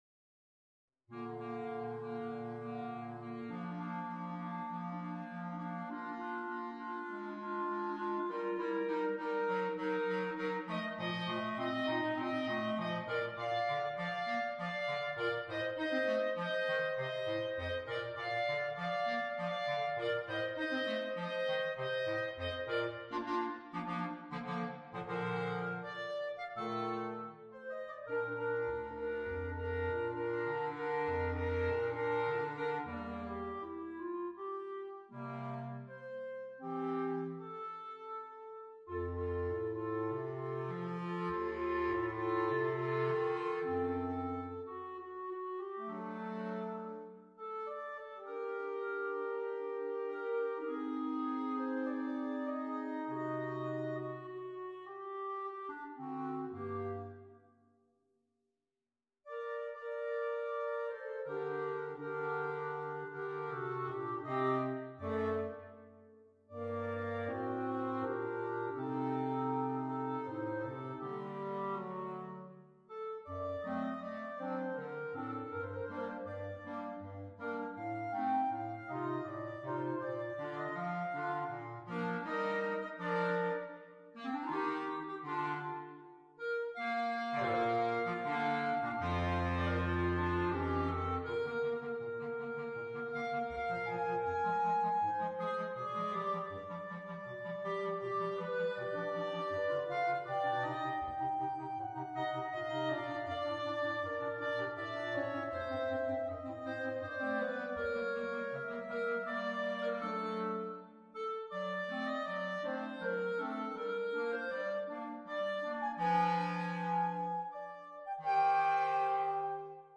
per quartetto di clarinetti